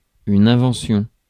Ääntäminen
IPA: [ɛ̃.vɑ̃.sjɔ̃]